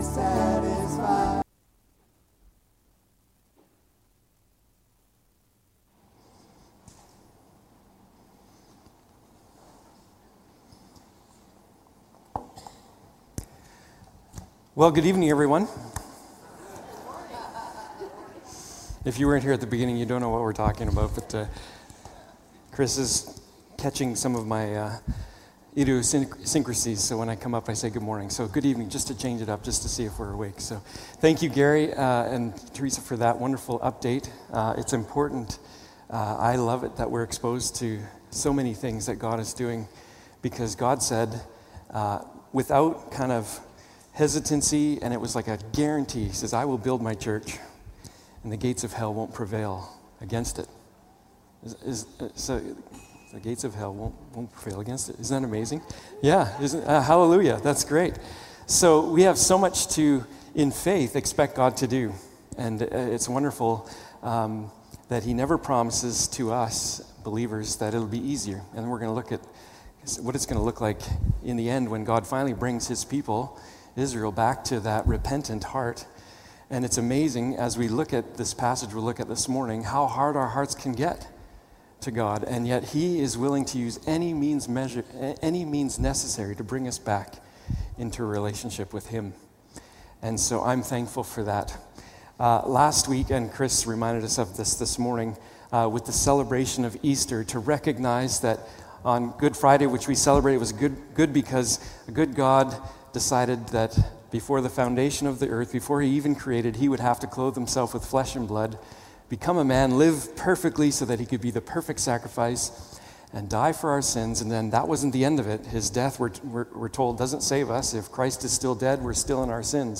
Passage: Mark 13 Service Type: Morning Service